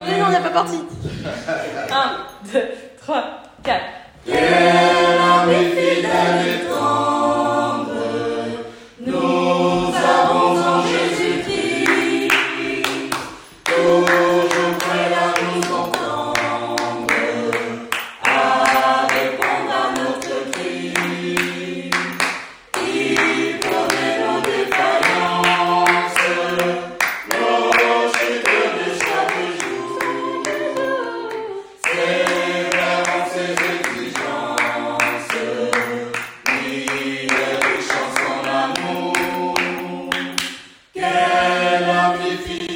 Chorale ouverte à toutes et à tous
Extrait audio de la chorale